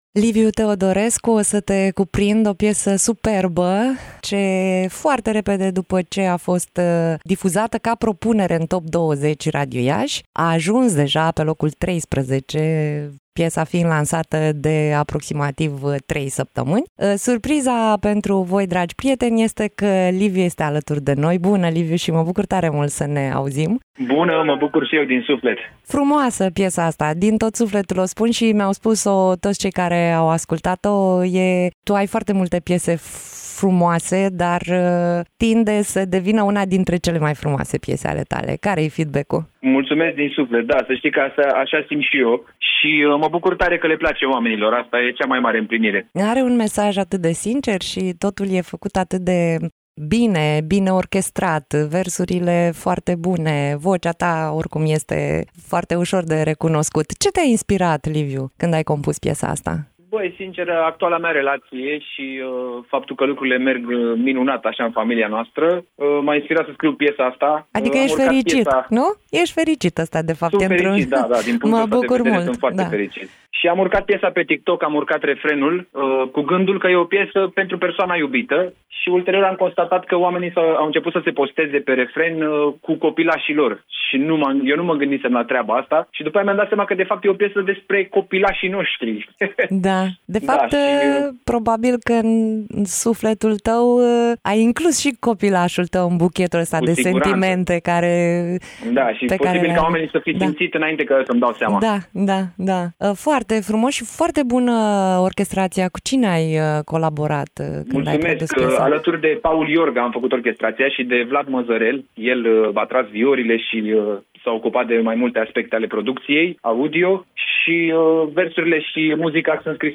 (INTERVIU) Liviu Teodorescu